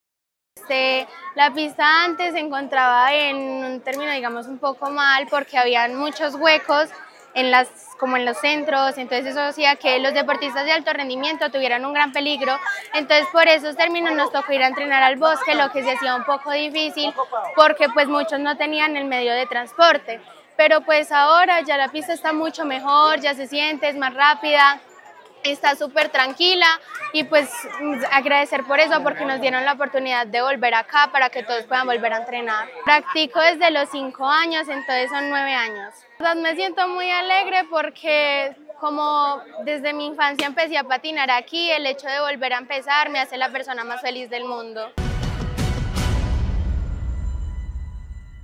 deportista villamariana.